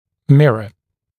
[‘mɪrə][‘мирэ]зеркало (в т.ч. инструмент)